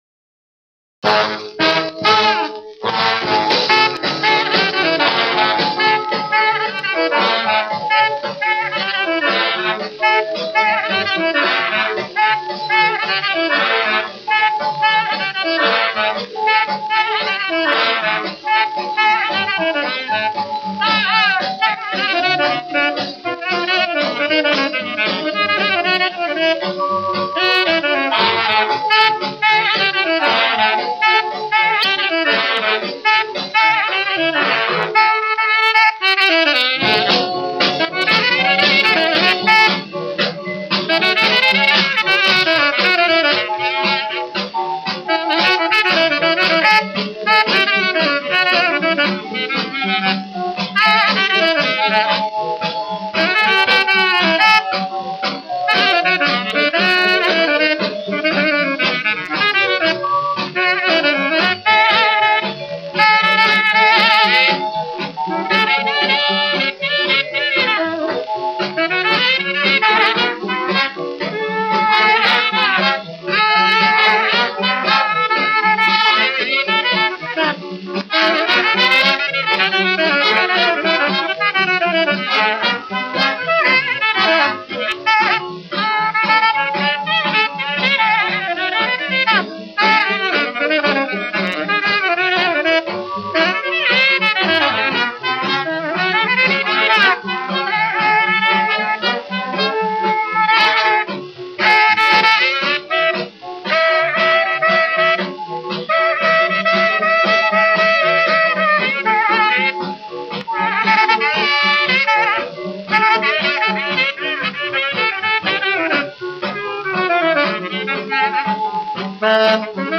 rhythm and blues tenor saxophonist